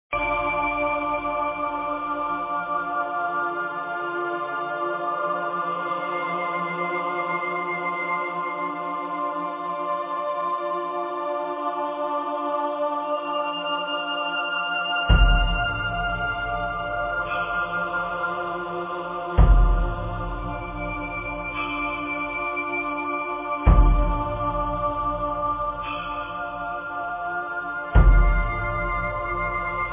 muzyka elektroniczna, ambient